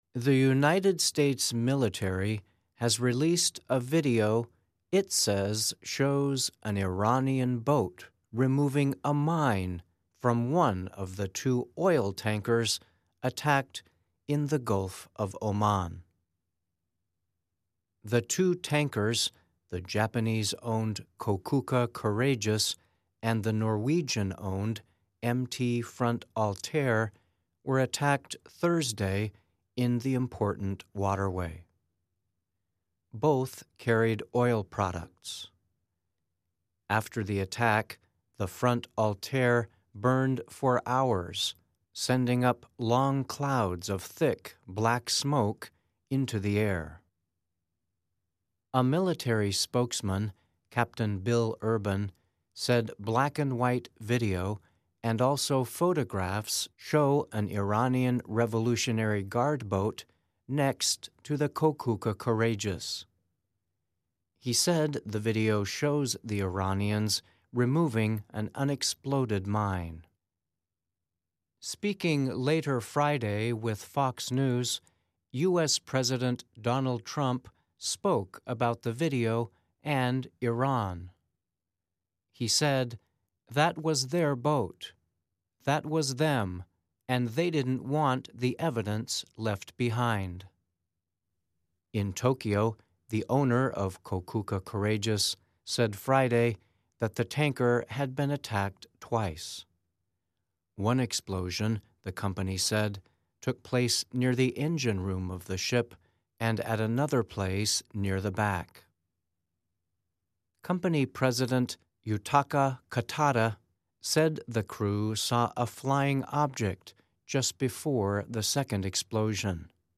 慢速英语:美军公布视频称伊朗海军移除未爆炸水雷